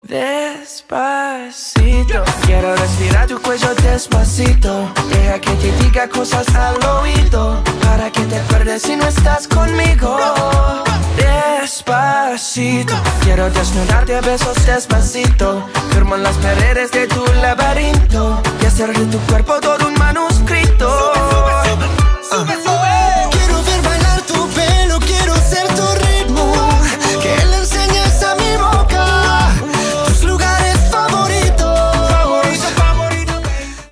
Ремикс
латинские # клубные